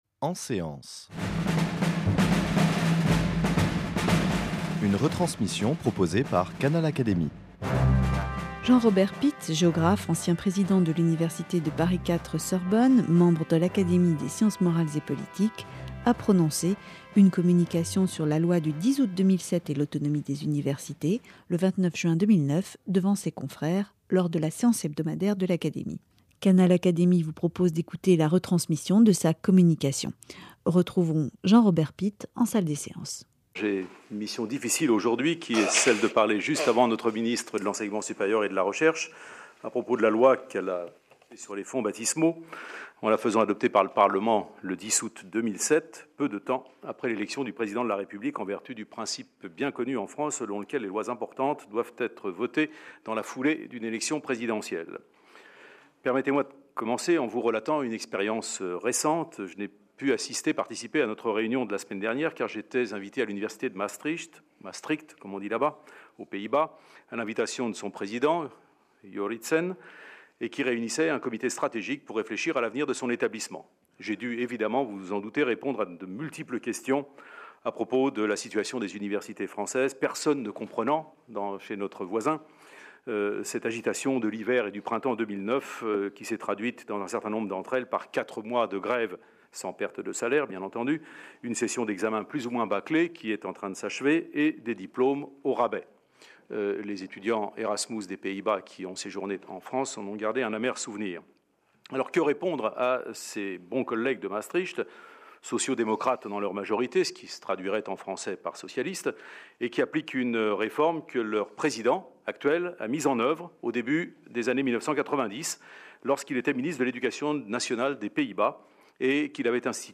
Jean-Robert Pitte, ancien président de l’université de Paris IV-Sorbonne (2003 - 2008), membre de l’Académie des sciences morales et politiques, s’est exprimé sur La loi de 2007 portant sur les libertés et les responsabilités des universités , devant ses confrères de l’Académie, le 29 juin 2009. Canal Académie vous propose d’écouter la retransmission de sa communication.